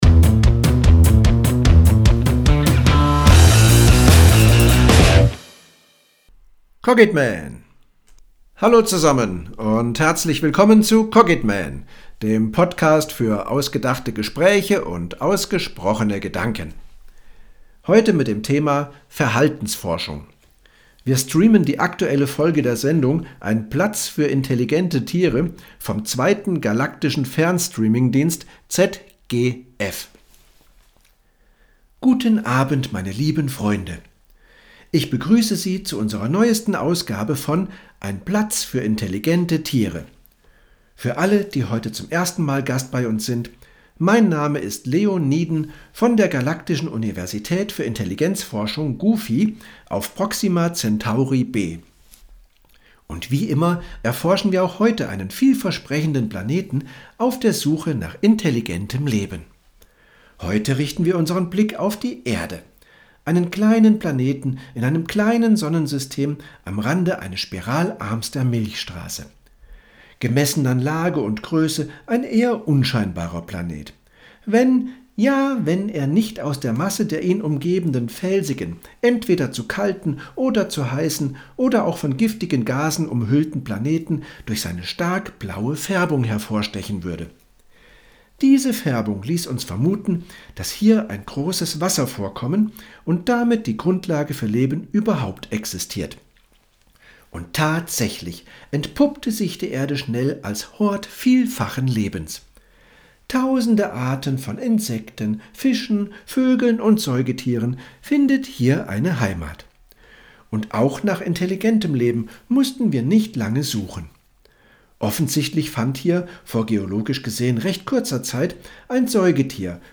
Monolog-Verhaltensforschung.mp3